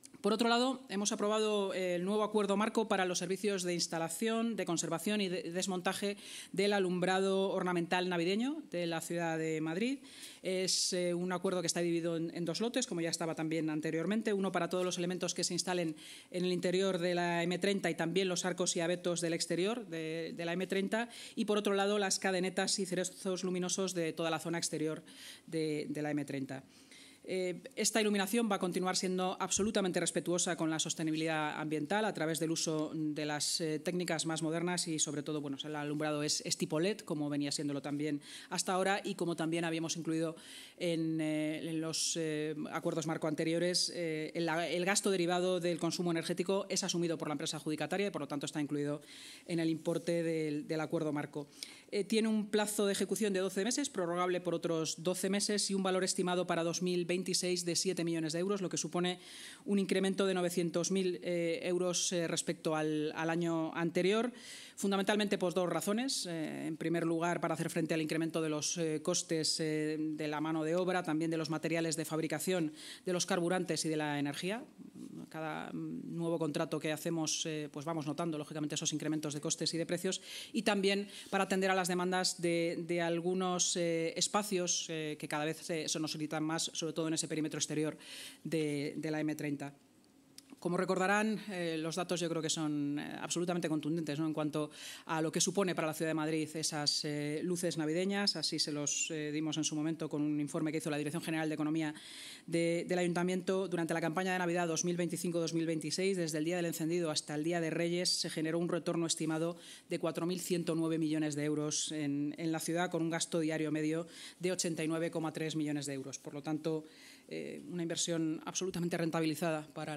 Nueva ventana:La vicealcaldesa de Madrid y portavoz municipal, Inma Sanz: